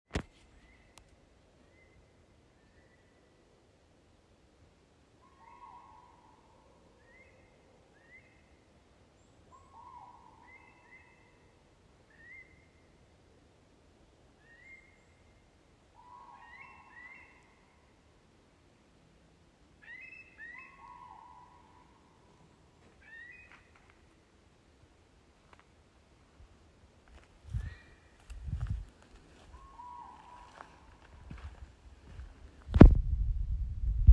Birds -> Waders ->
Eurasian Curlew, Numenius arquata
StatusPasses over (transit)